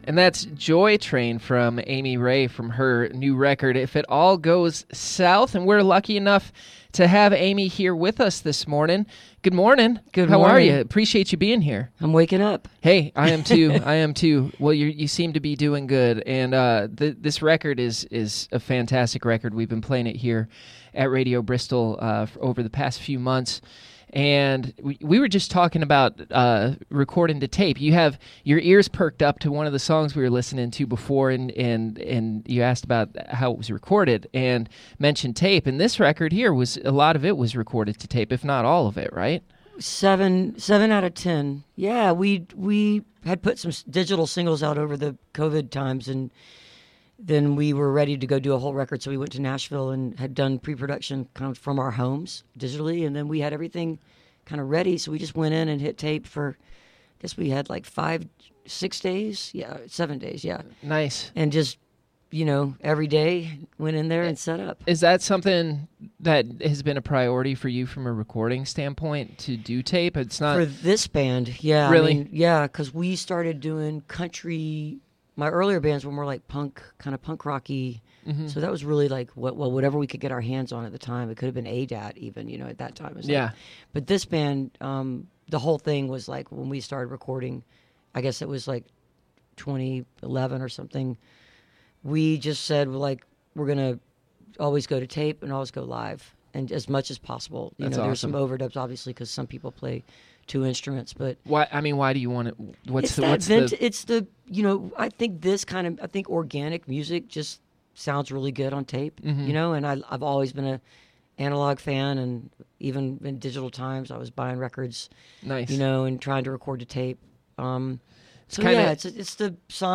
(captured from a webcast)
04. interview (9:25)